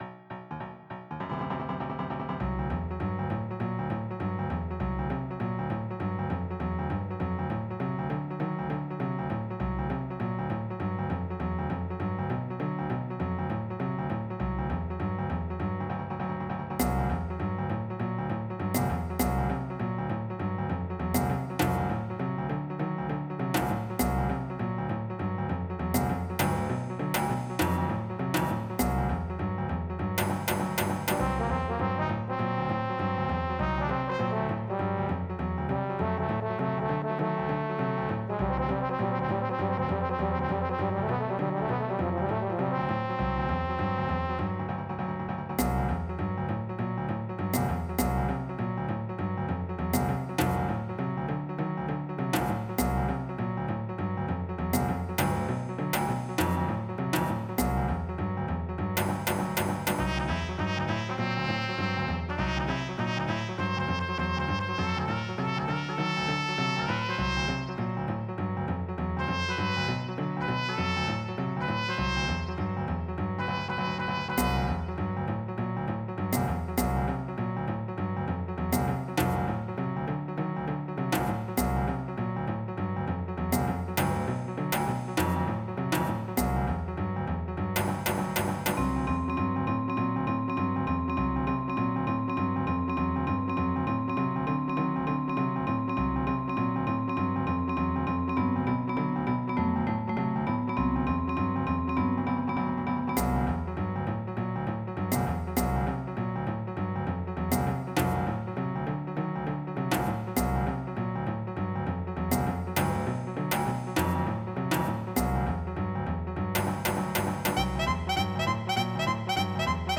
Type General MIDI
JAZZ1.mp3